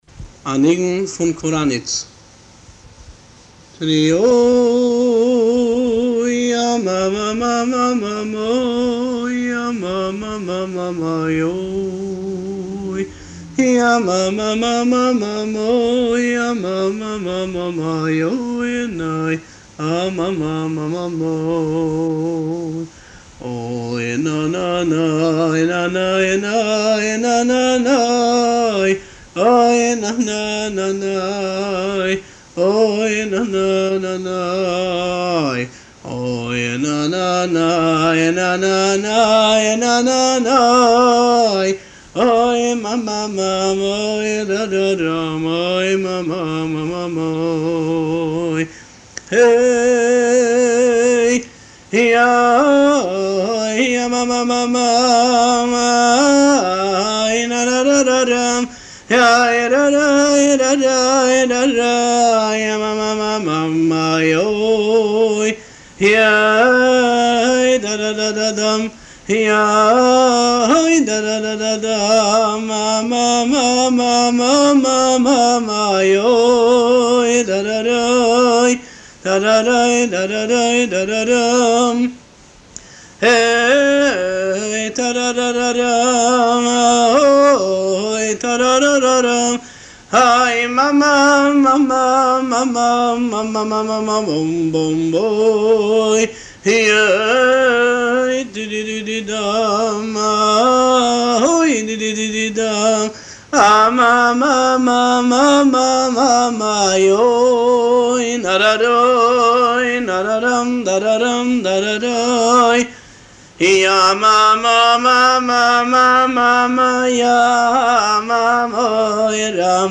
ניגון מקורניץ הוא ניגון חסידי שלא נכלל בספר הניגונים שמקורו בעיירה החסידית קורַאניץ.